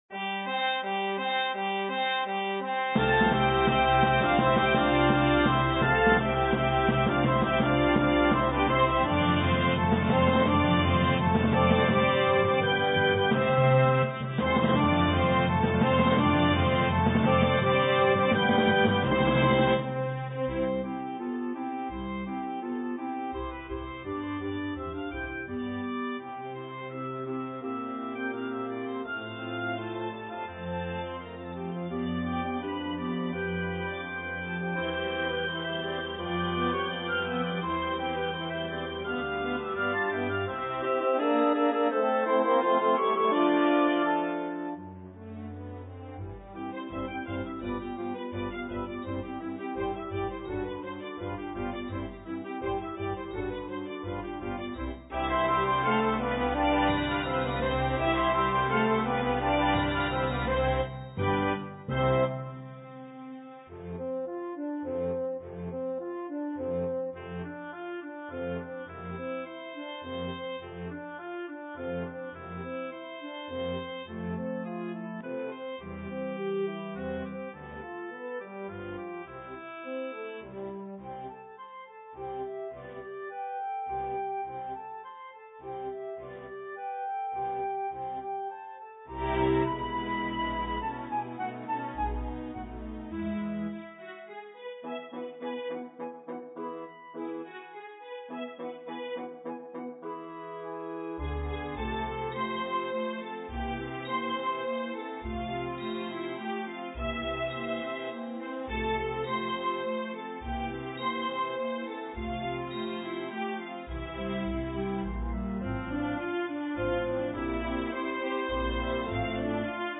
Orchestration
2 Flutes, 2 Oboes, 2 Clarinets in Bb, 2 Bassoons
Strings (Violin 1, Violin 2, Viola, Cello, Bass)